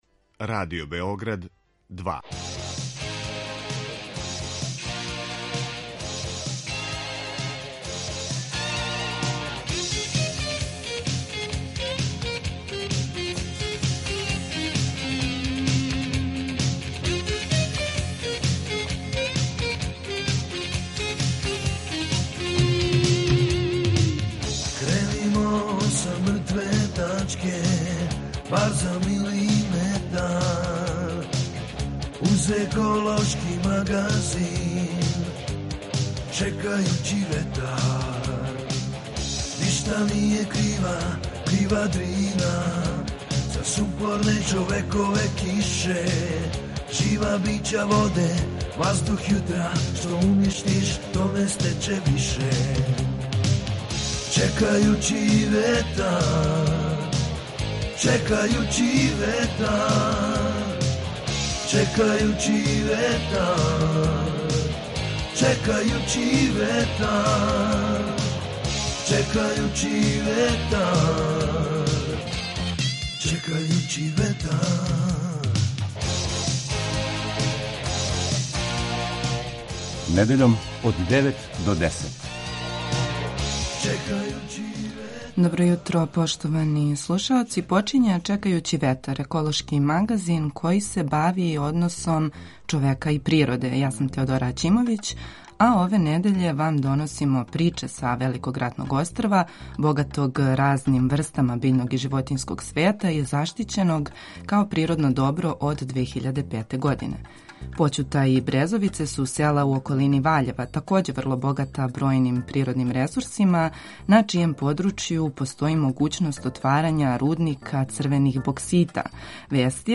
Čekajući vetar - ekološki magazin Radio Beograda 2 koji se bavi odnosom čoveka i životne sredine, čoveka i prirode.